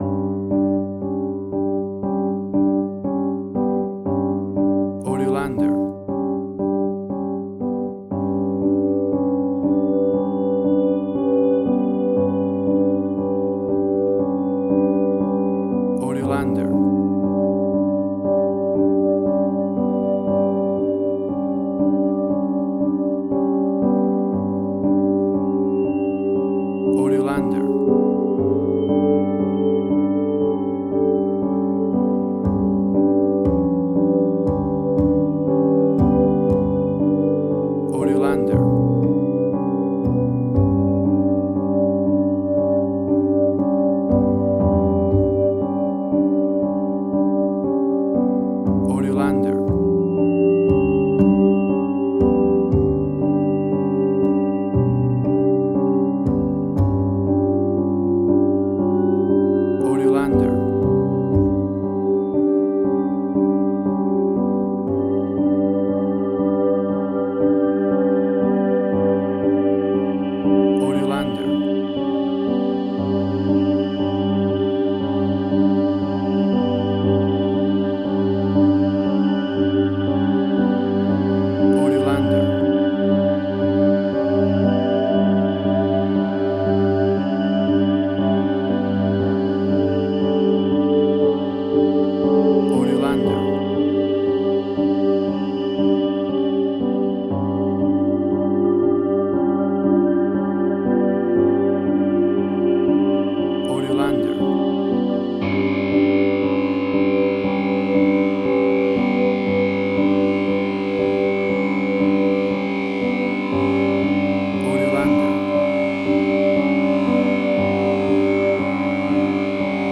Post-Electronic.
Tempo (BPM): 60